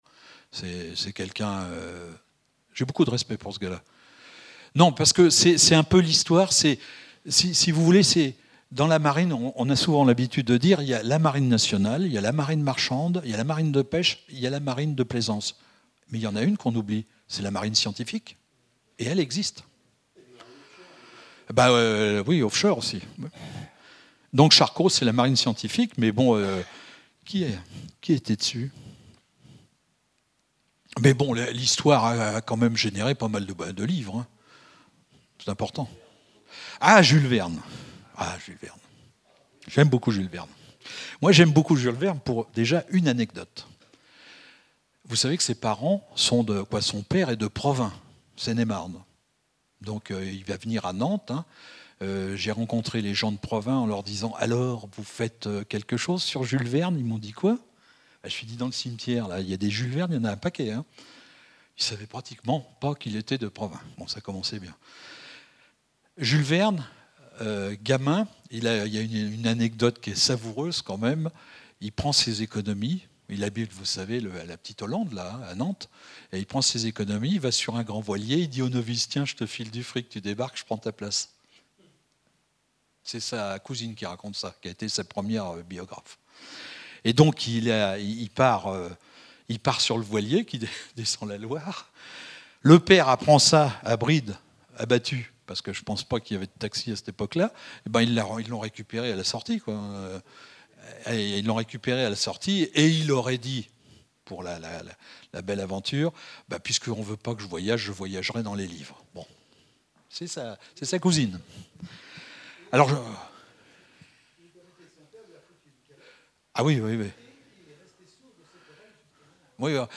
Conférence au Vasais
Catégorie Témoignage